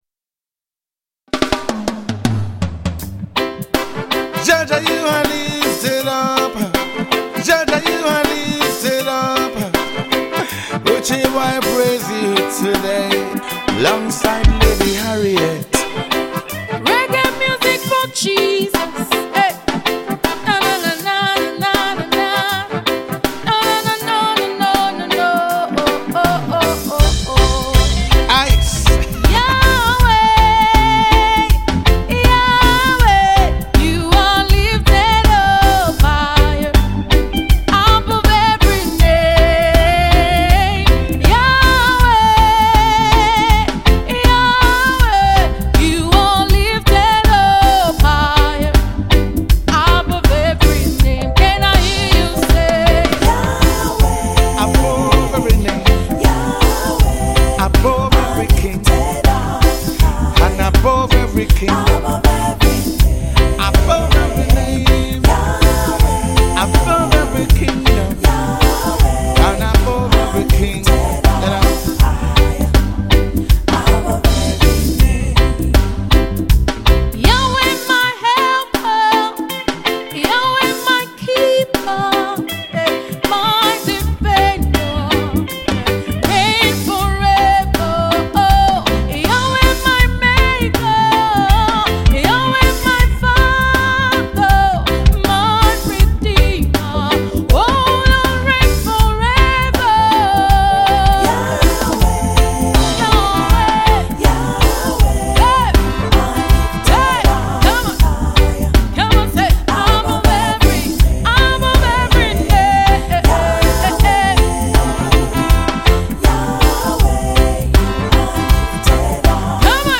gospel reggae